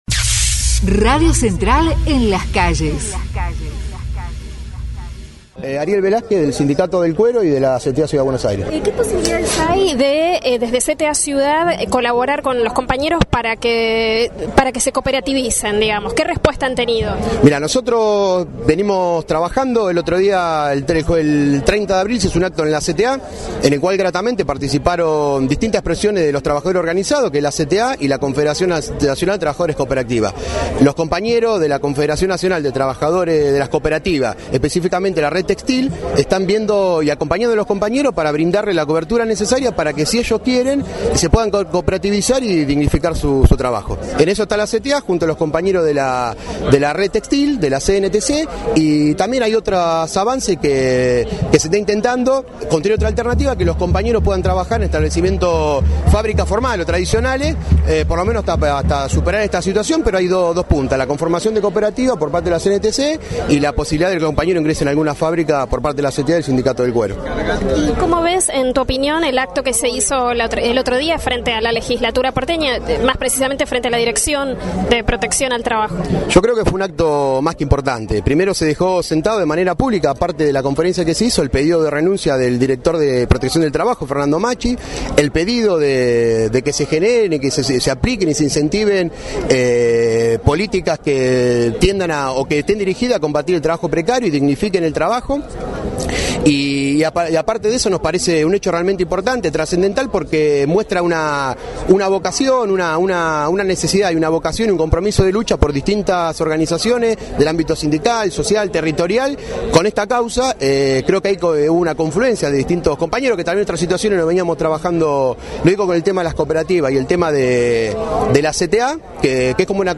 MARCHA CONTRA TALLERES CLANDESTINOS